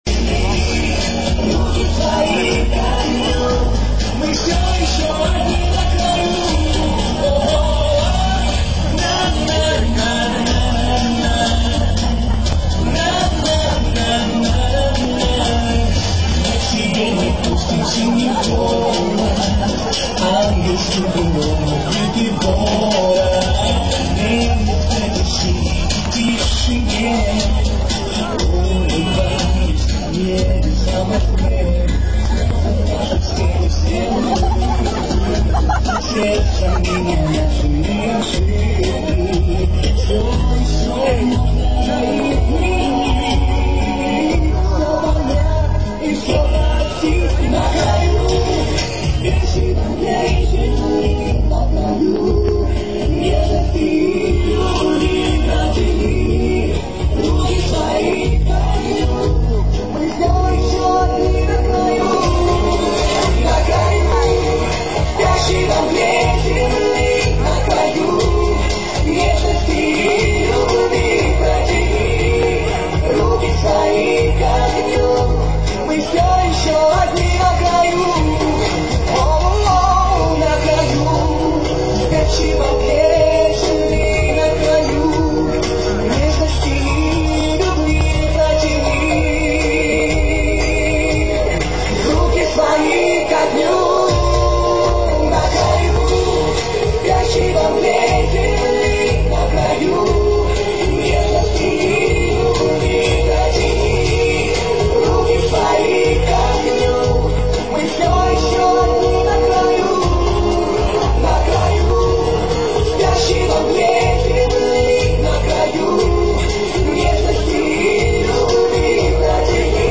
НОВОСИБИРСК, ПЛОЩАДЬ ЛЕНИНА, 4 НОЯБРЯ 2005 ГОДА